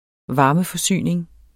varmeforsyning substantiv, fælleskøn Bøjning -en, -er, -erne Udtale [ ˈvɑːmə- ] Betydninger 1. produktion og fordeling af varme til boliger, virksomheder, landbrug osv.